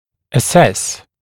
[ə’ses][э’сэс]оценивать, давать оценку, определять